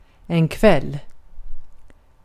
Ääntäminen
Tuntematon aksentti: IPA : /ˈnaɪt/